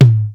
Rhythm Machine Sound "Drumulator"
midtom.wav